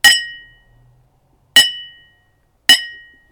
Glass Ding / Toast with champagne glasses
champagne cling clink clinking ding glass glasses ting sound effect free sound royalty free Sound Effects